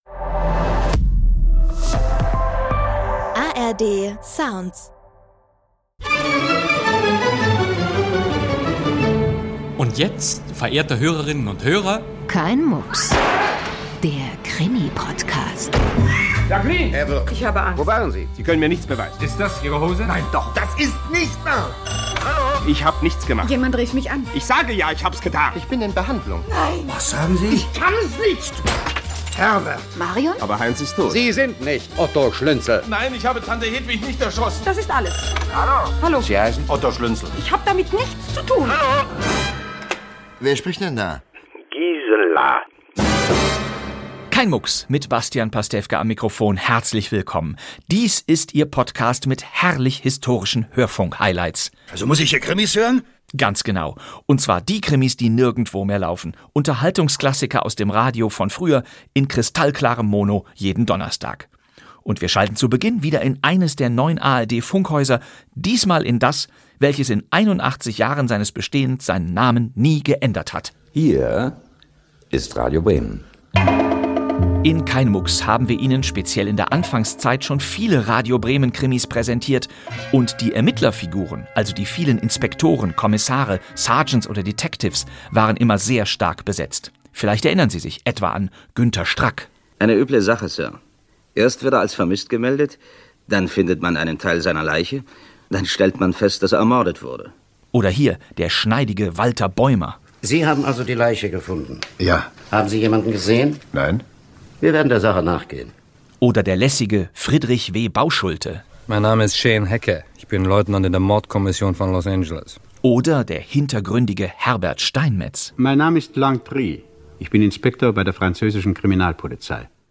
Hörspiel No. 188 Ein Anruf aus Undeloh [Staffelfolge 7.11] 2026-03-19.